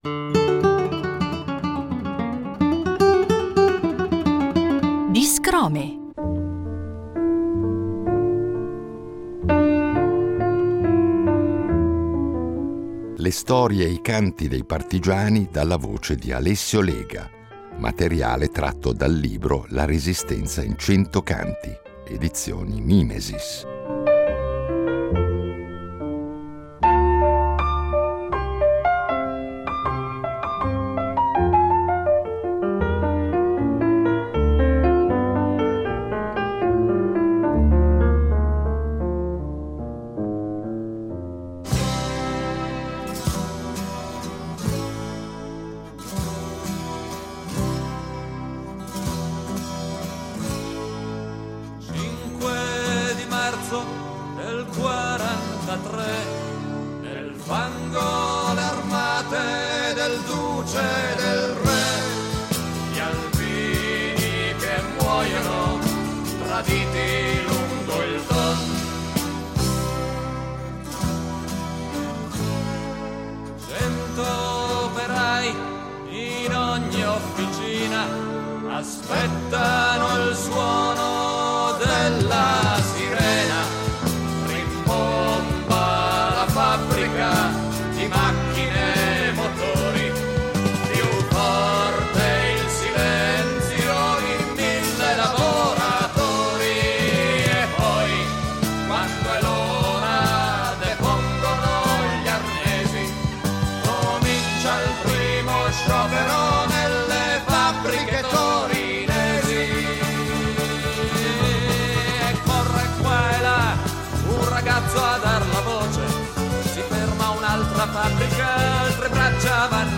Le storie e le canzoni della Resistenza dalla voce e dalla chitarra